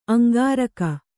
♪ aŋgāraka